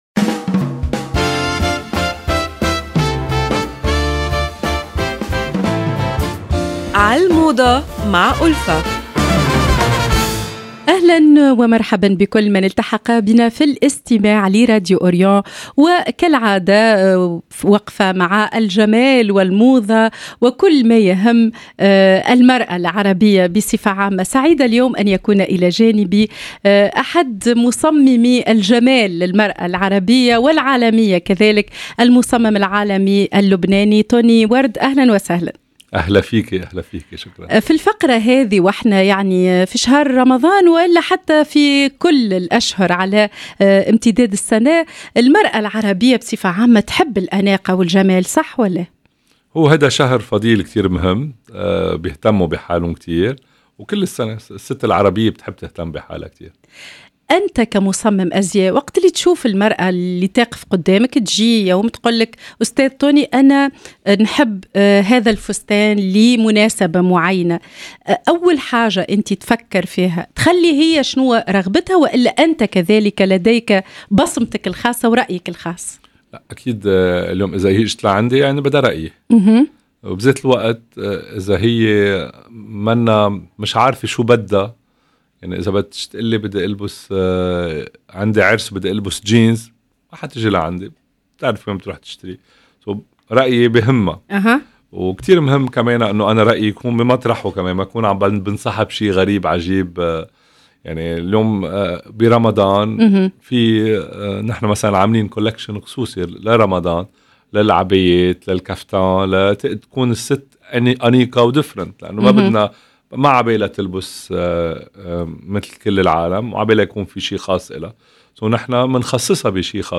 حيث نستضيف المصمم العالمي اللبناني طوني ورد الذي حدثنا عن أبرز لمسات الموضة لهذا الموسم في لقاء حمل الكثير من الإلهام والرقي.